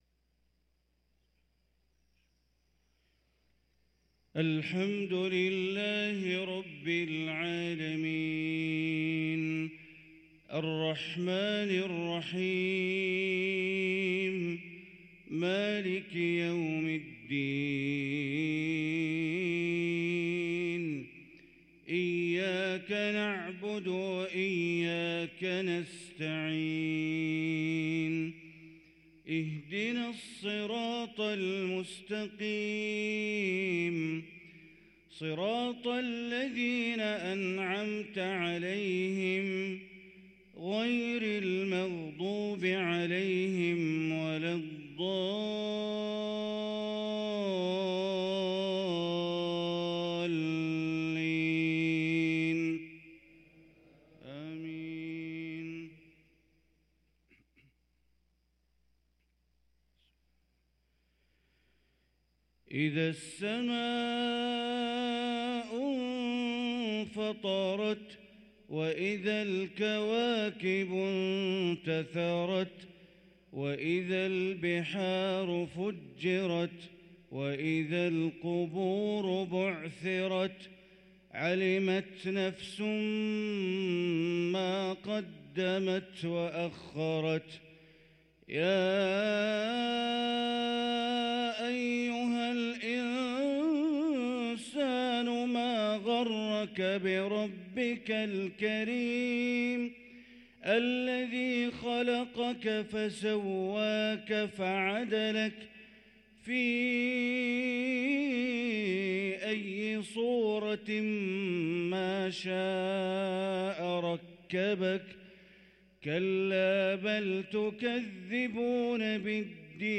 صلاة الفجر للقارئ بندر بليلة 19 رجب 1444 هـ